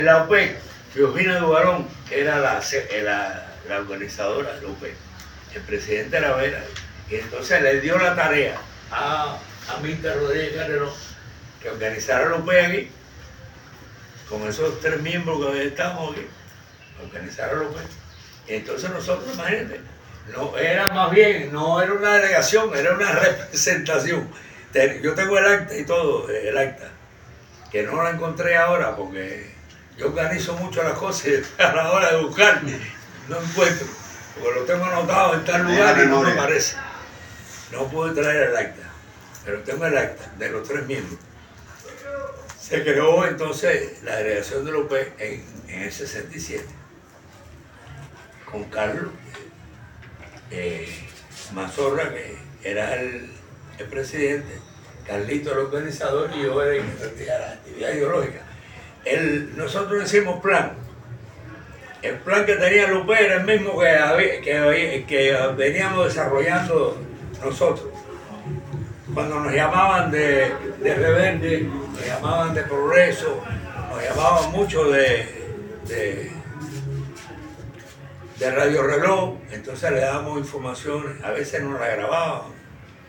El encuentro no sumó años, más bien sapiencia e historia contenida en la memoria y el alma de jubilados de diferentes medios de comunicación, quienes fueron convocados por la Unión de Periodistas de Cuba (Upec) en la Isla de la Juventud, en ocasión de la Jornada de la Prensa Cubana.